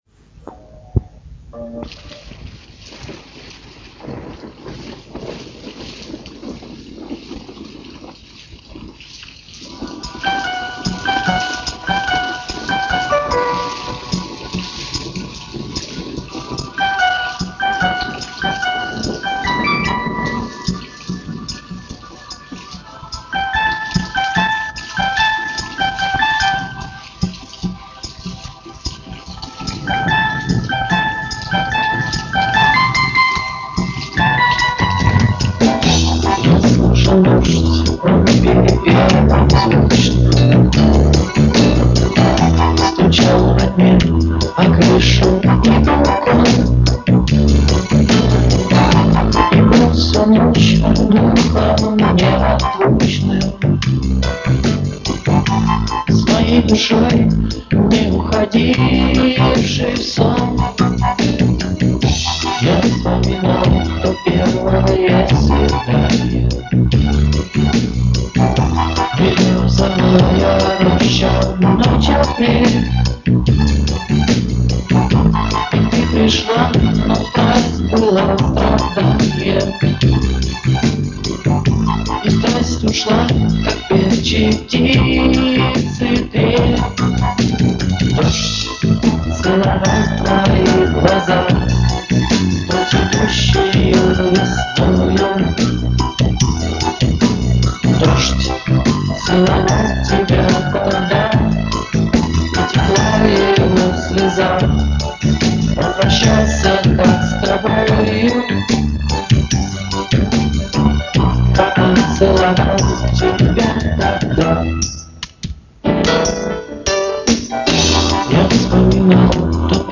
Но качество плохое, лучшего не нашла.